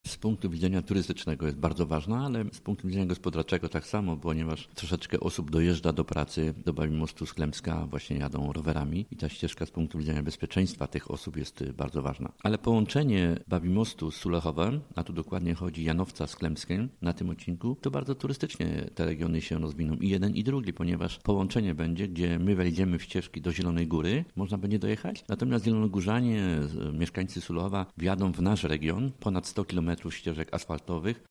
– W naszym przypadku sprawa prosta i oczywista, bo my ścieżkę rowerową doprowadziliśmy już prawie do granicy gminy – mówi Bernard Radny, burmistrz Babimostu.